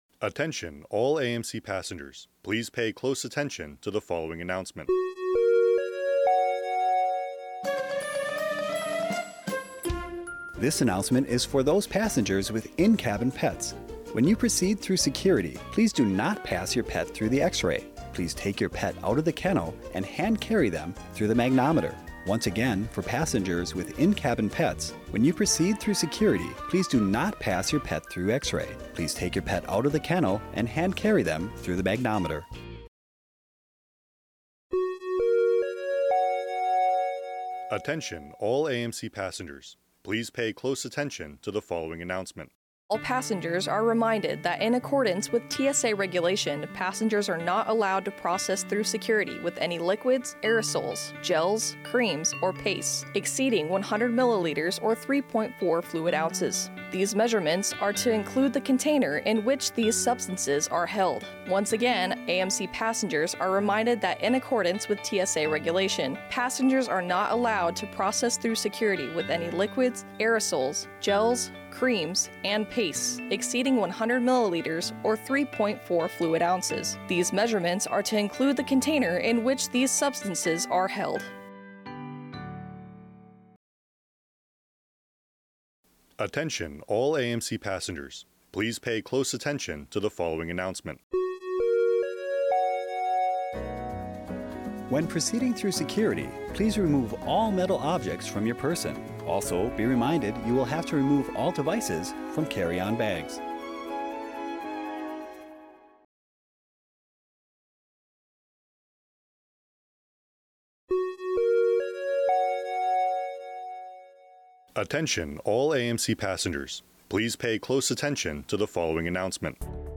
This readout contains messaging about TSA Regulations, securing your luggage, protecting your pets and tips for moving through security efficiently, narrated by service members assigned to Media Bureau Japan.